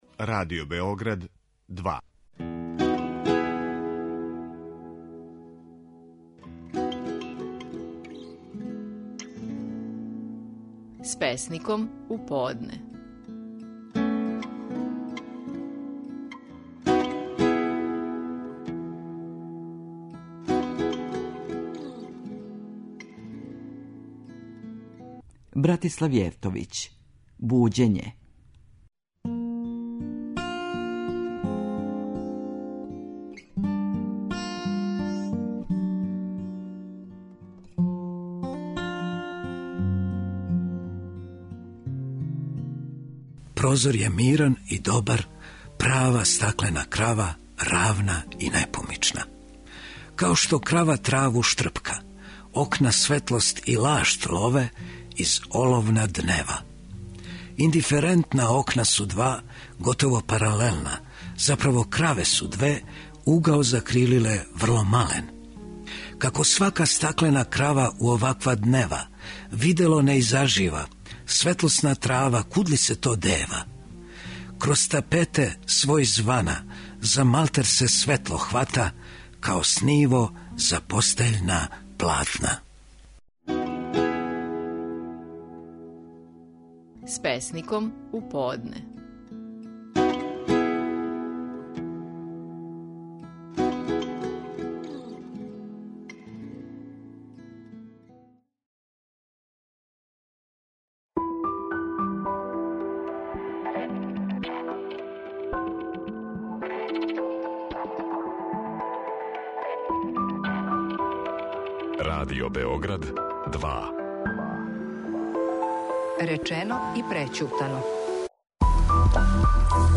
верски аналитичар.